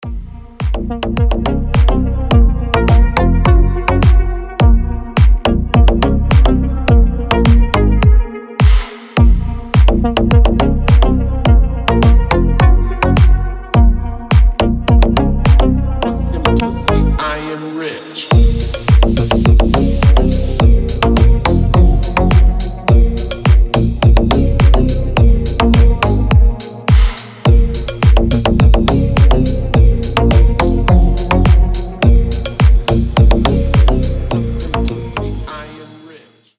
deep house
басы
восточные
G-House
Качёвый клубняо